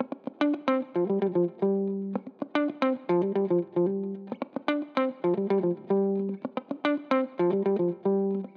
06 Guitar PT2.wav